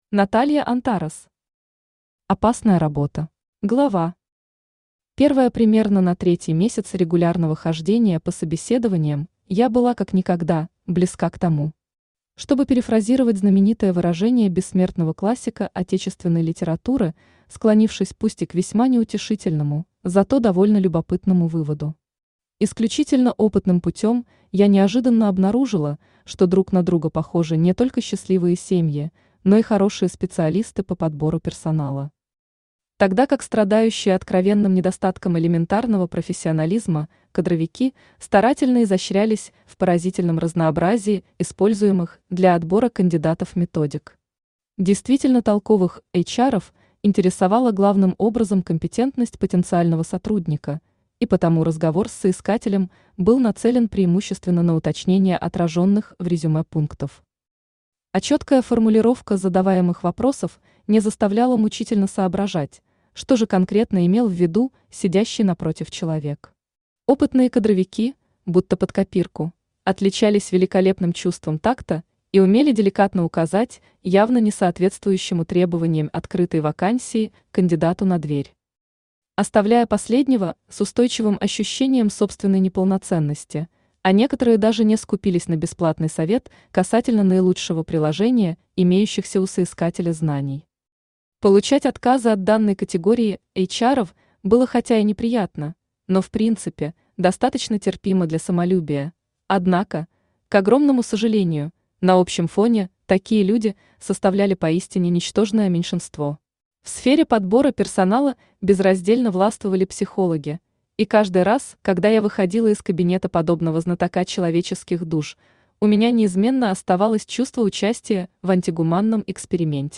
Аудиокнига Опасная работа | Библиотека аудиокниг
Aудиокнига Опасная работа Автор Наталья Антарес Читает аудиокнигу Авточтец ЛитРес.